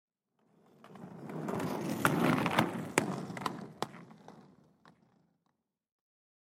Звуки роликовых коньков
Звук прокатившегося мимо человека на роликах